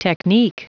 Prononciation du mot technique en anglais (fichier audio)
Prononciation du mot : technique